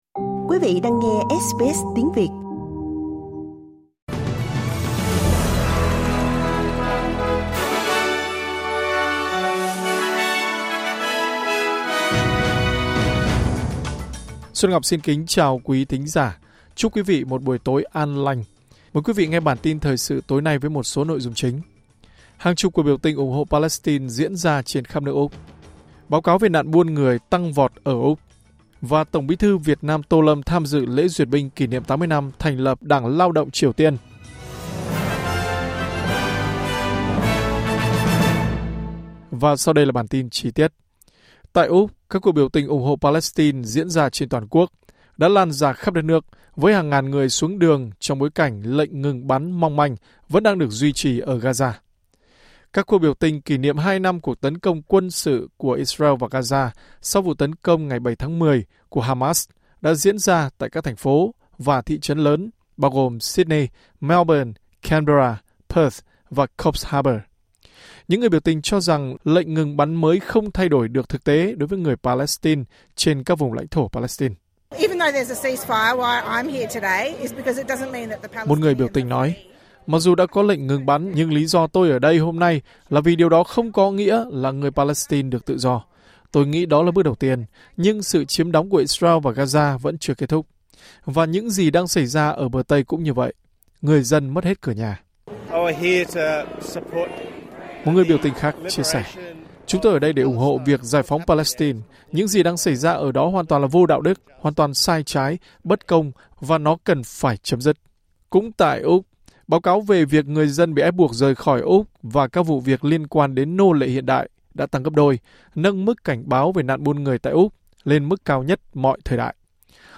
Bản tin của SBS Tiếng Việt sẽ có những nội dung chính.